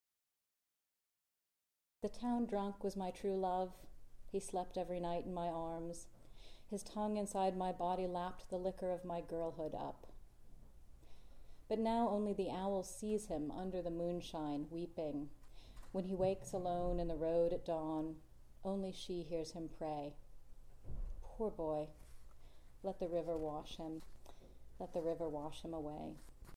recorded live at Bowdoin College,